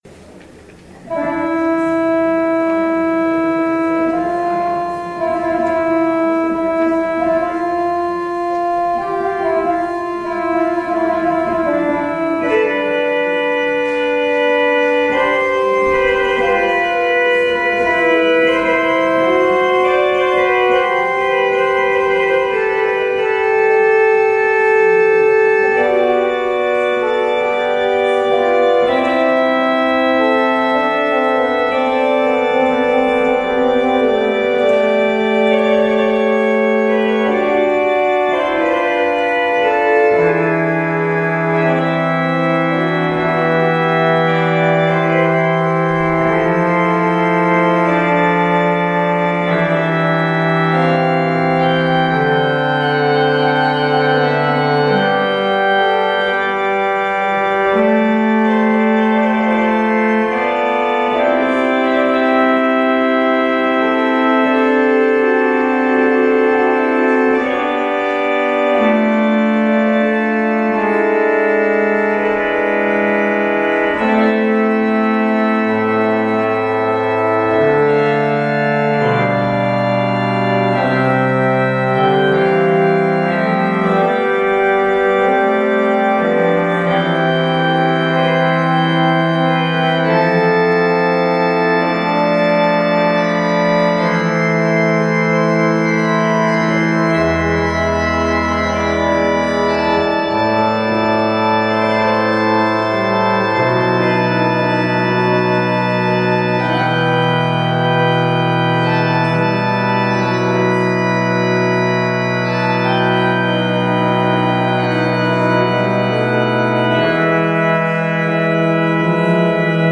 Audio recording of the 10am hybrid/streamed service